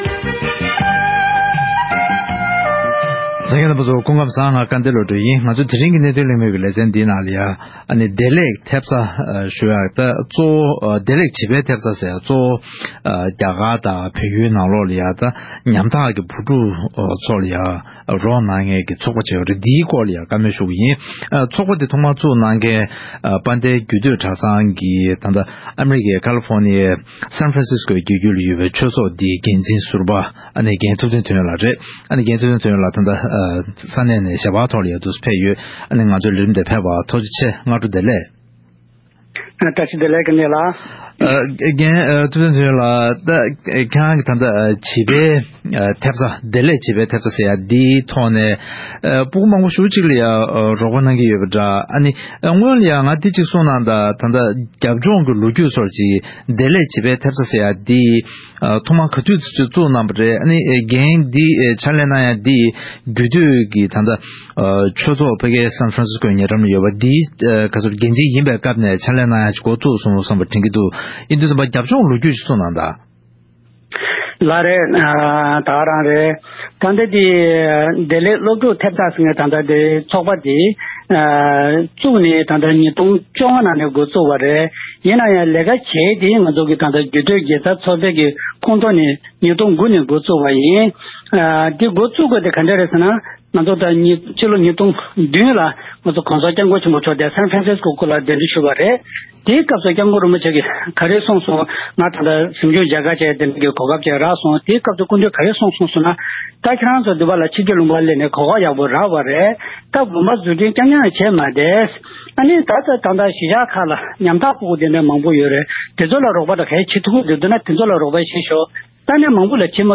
གནས་དོན་གླེང་མོལ